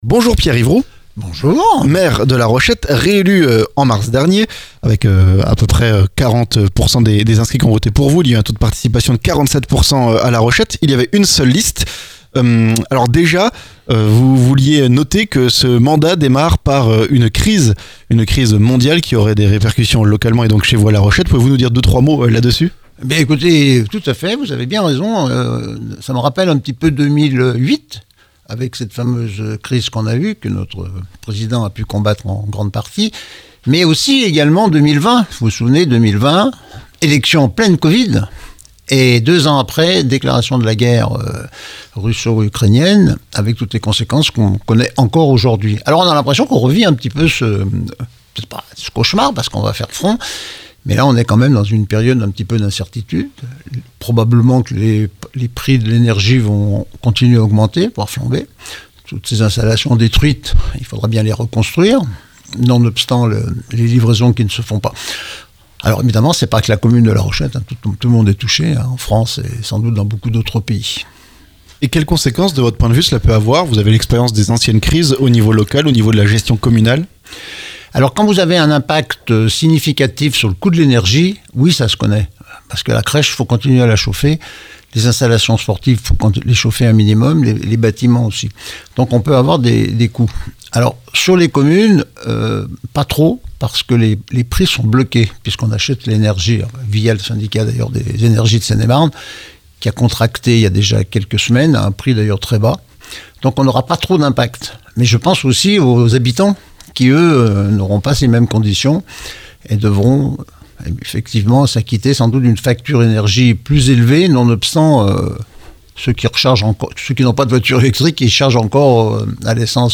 LA ROCHETTE - Entretien avec le maire réélu, Pierre Yvroud
Crise énergétique, nouveaux médecins, sécurité, intercommunalité... Ecoutez notre entretien avec Pierre Yvroud, le maire de La Rochette.